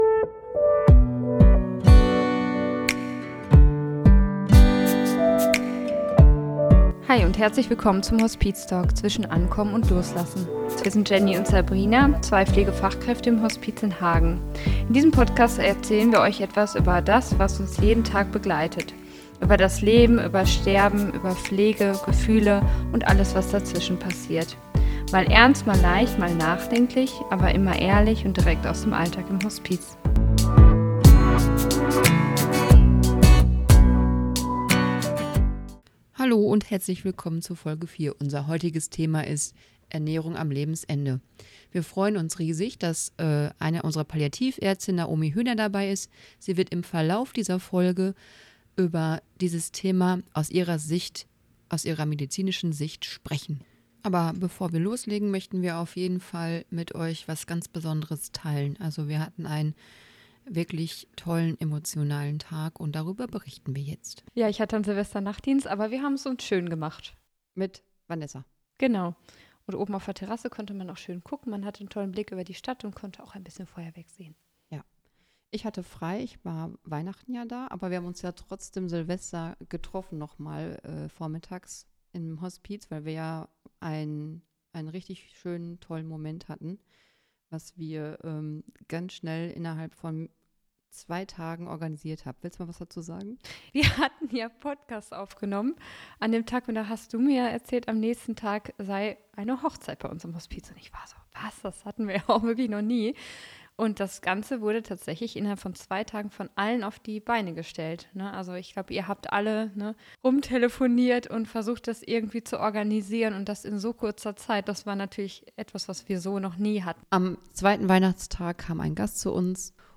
Im Gespräch tauschen wir unsere Erfahrungen aus dem Hospizalltag aus und sprechen offen über die Fragen und Unsicherheiten, die Angehörige/Zugehörige rund um das Thema bewegen.